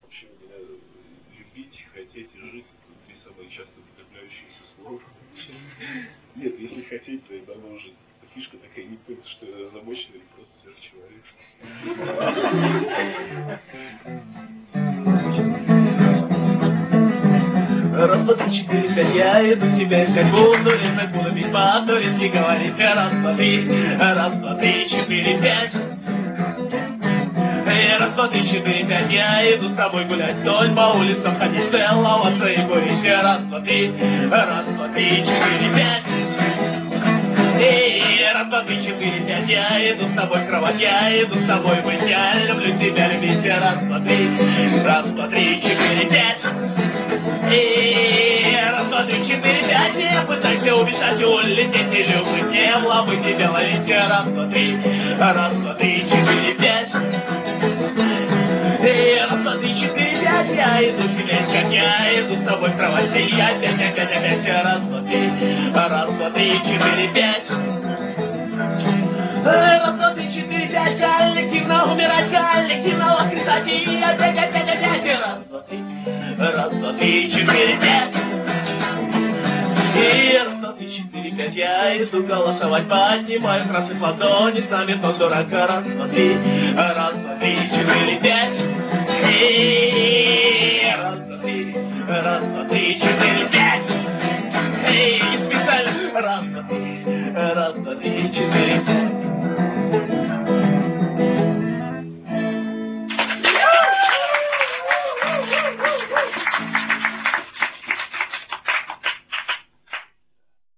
Квартирник 26 октября 1999.